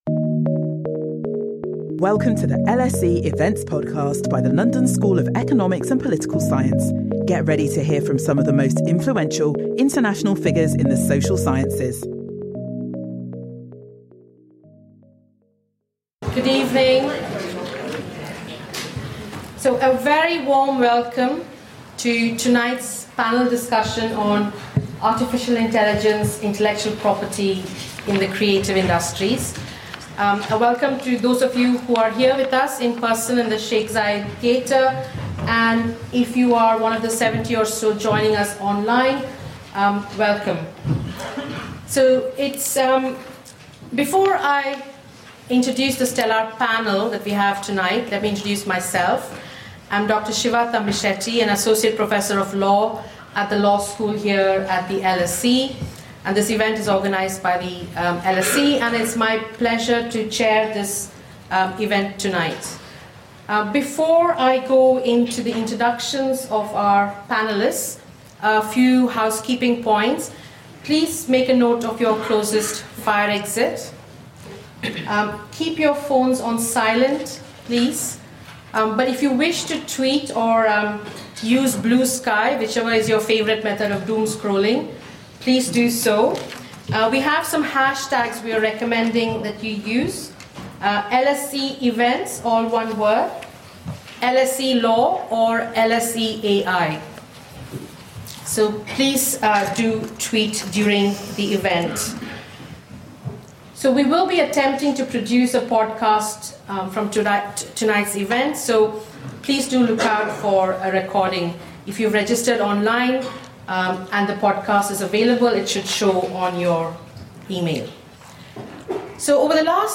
The panel will debate Intellectual Property Law issues related to the training and use of generative AI models that produce works of text, art and music, such as ChatGPT and Stable Diffusion, and will discuss the use of AI in the context of image rights of performers.